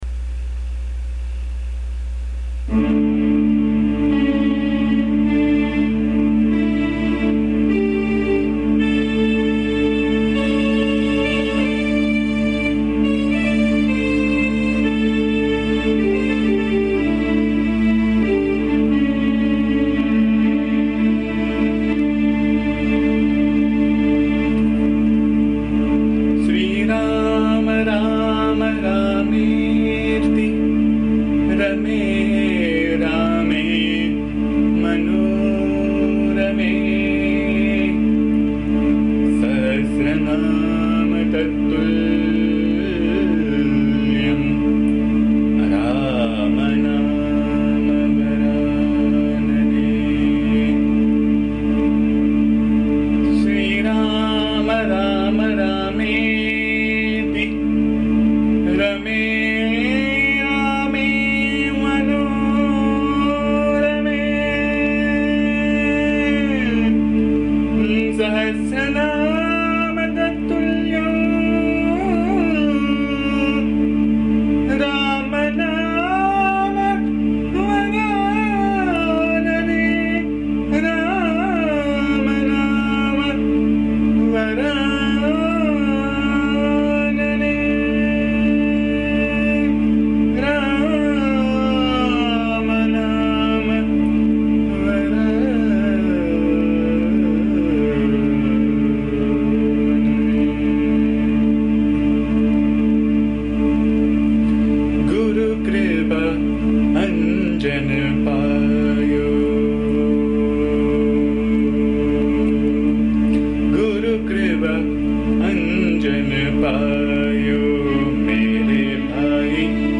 This is a very beautiful song on Rama set in Raga Brindavani Sarang. Here the poet speaks about Rama pervading everything which is the experience of a realized saint.
The song has been recorded in my voice which can be found here. Please bear the noise, disturbance and awful singing as am not a singer.